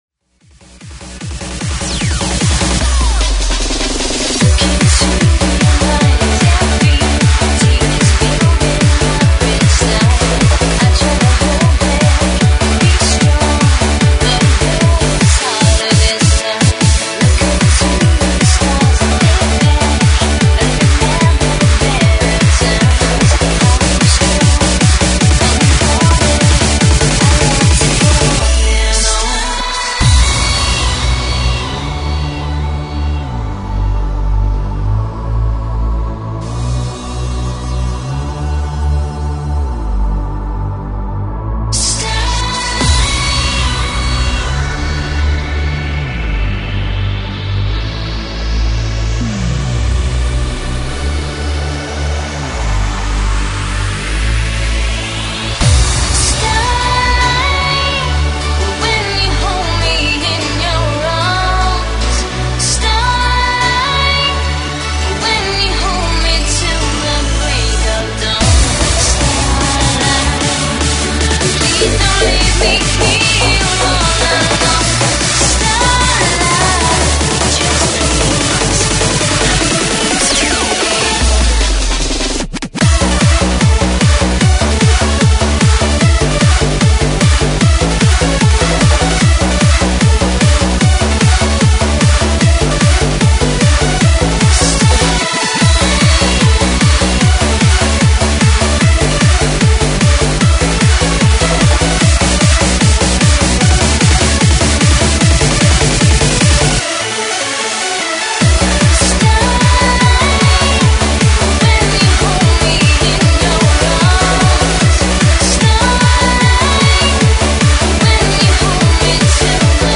Hard House/Hard Trance